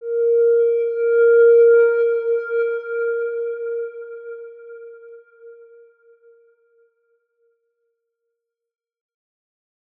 X_Windwistle-A#3-pp.wav